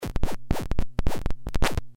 铅01
描述：BPM130非EQ非Reverb非Widener。使用Steinberg HalionSonic2 Trium创造了这种声音。
标签： 环路 电子 合成器 电子乐 房子 舞蹈
声道立体声